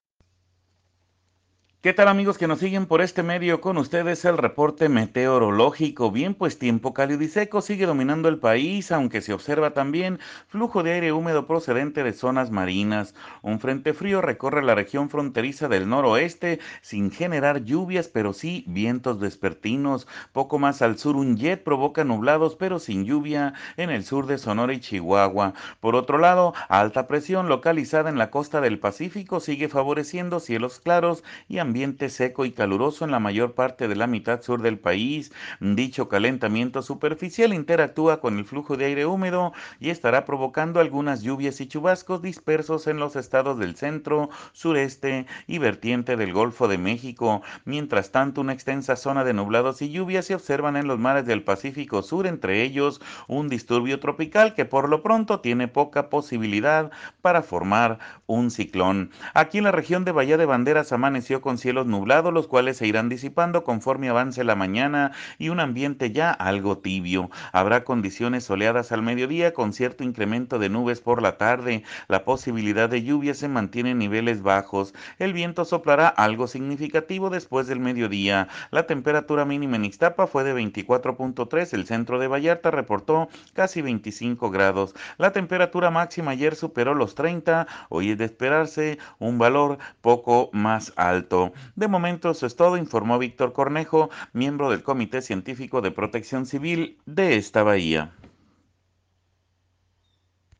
AUDIO: escuche al meteorólogo